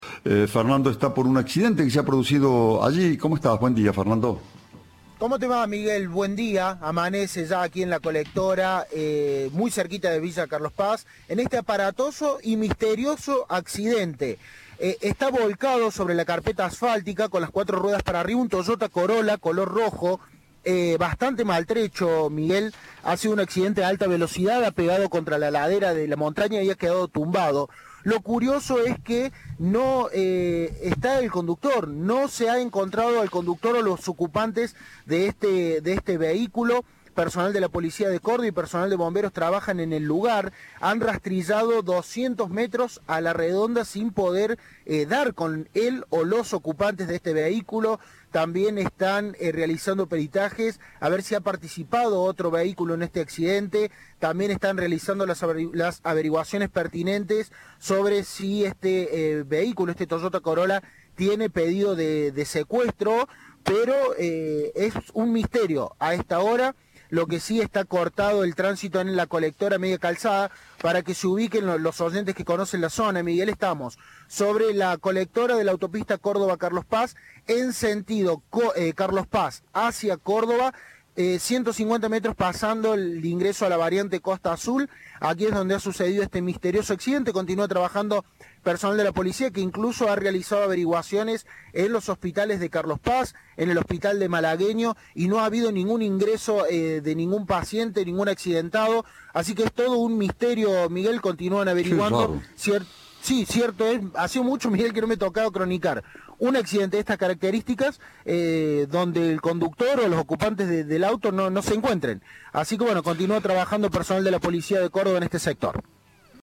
Informes